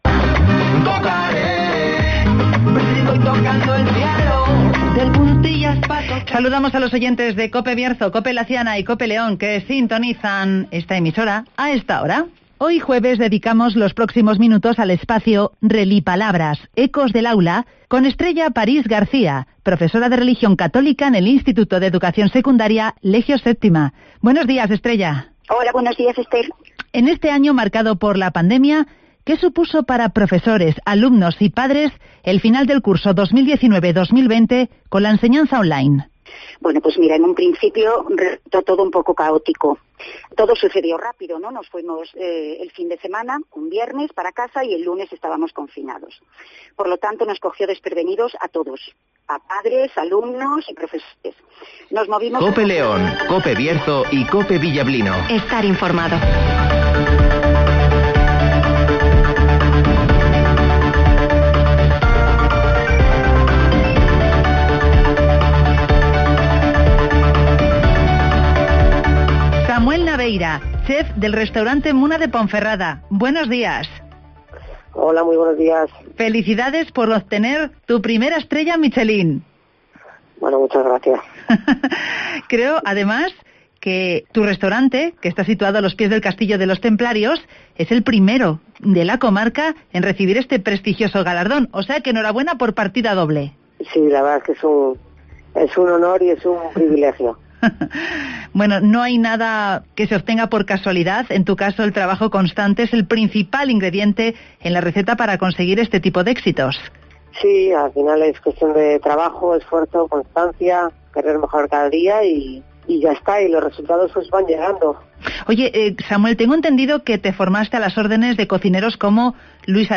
El restaurante Muna de Ponferrada obtiene la primera estrella Michelín de la comarca del Bierzo (Entrevista